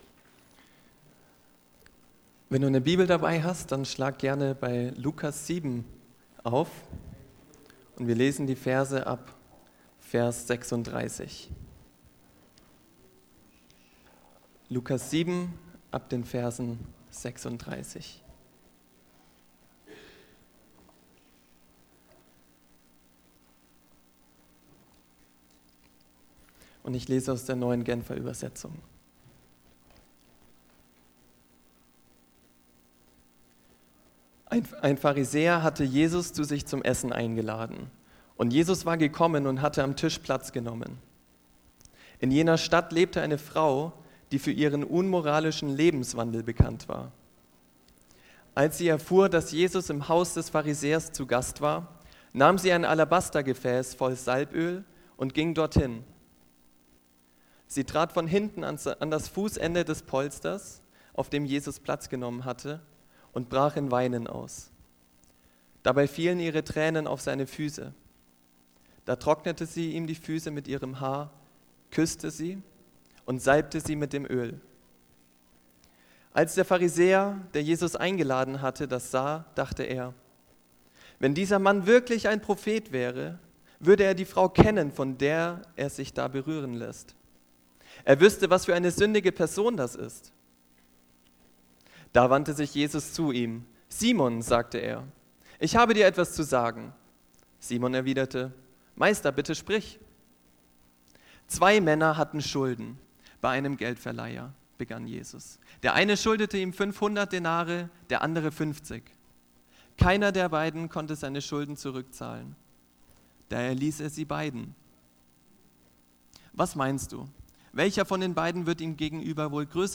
Gott lieben – mehr als ein Gefühl | Marburger Predigten